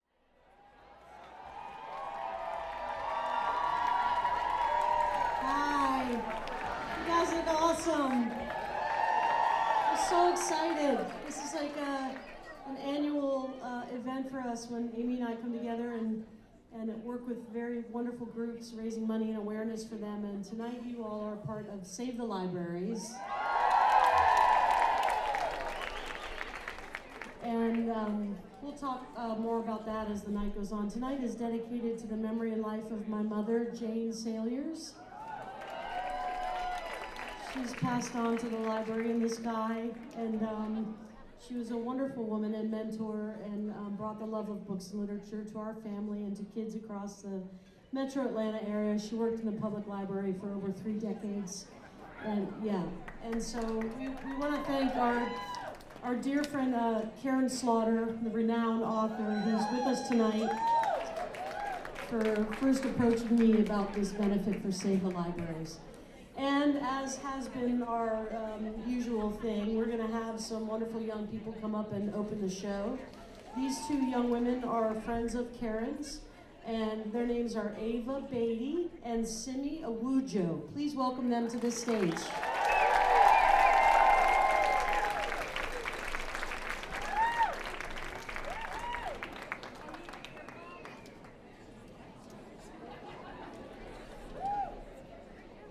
lifeblood: bootlegs: 2016-01-03: terminal west - atlanta, georgia (benefit for save the libraries)